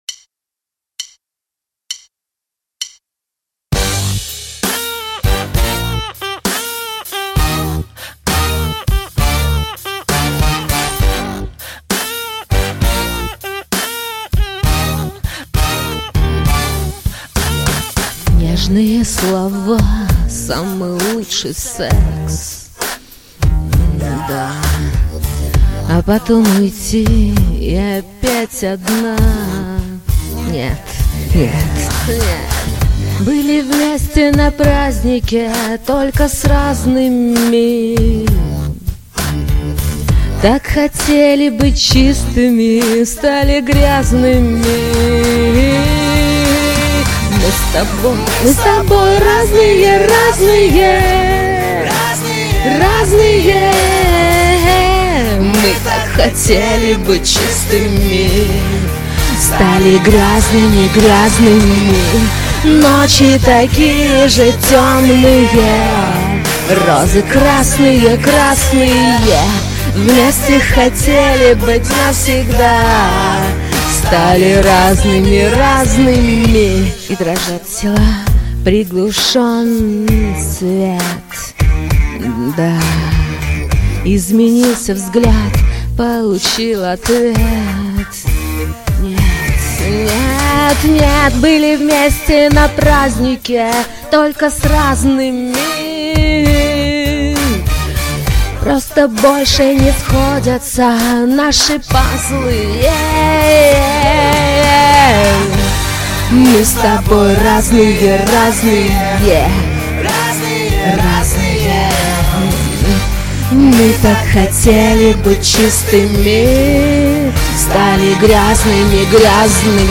интонации завораживающие и "ледяные"